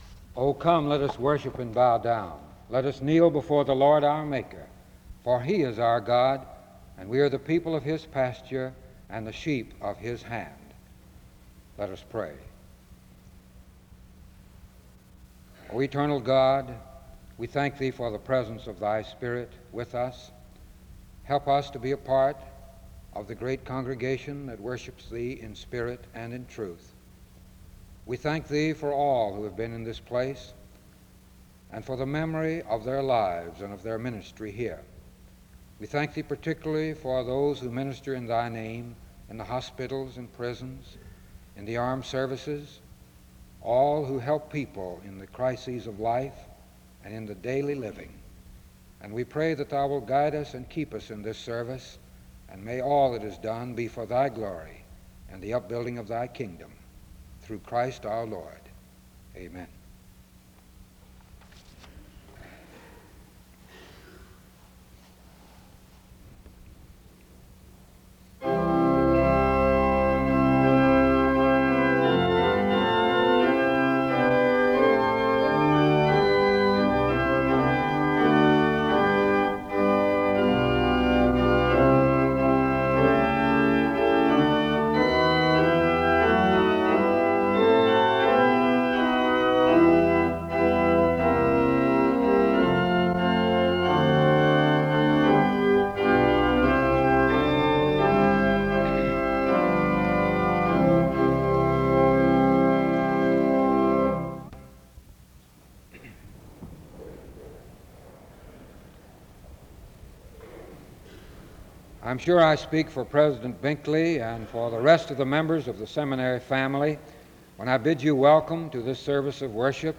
The service begins with a word of prayer and music from 0:00-1:45. There are announcements made from 1:51-5:00. An introduction to the speaker is given from 5:29-6:20.